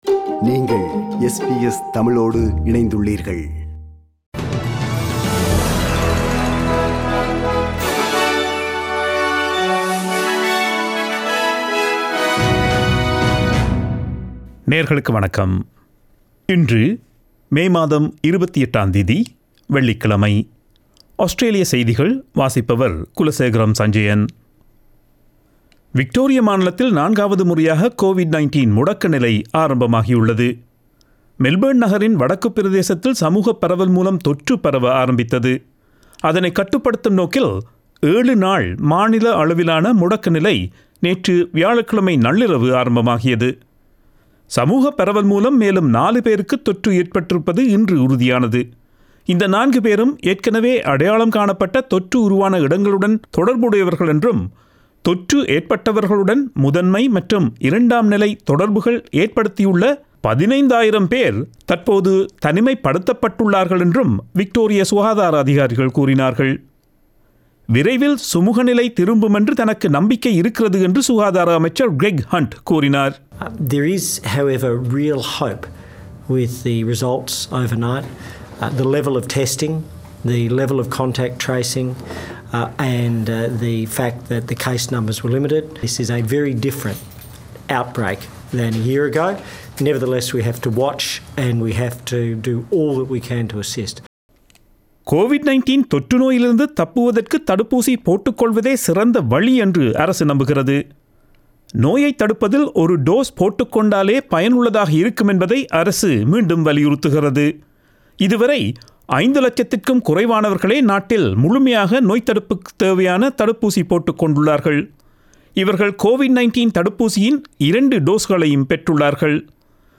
Australian News: 28 May 2021 Friday